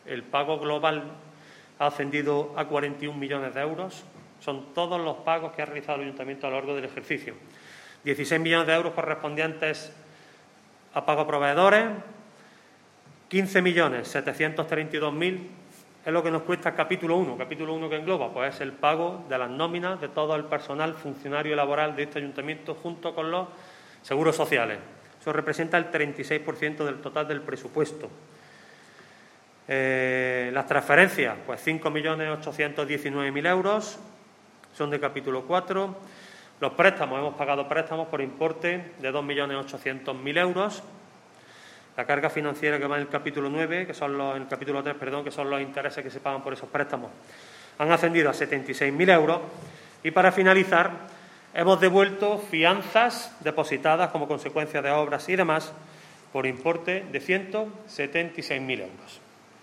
El teniente de alcalde delegado de Hacienda, Antonio García, ha comparecido en la mañana de hoy ante los medios de comunicación para resumir la actividad económica del Ayuntamiento de Antequera durante el pasado año 2020 en lo que a pagos se refiere.
Cortes de voz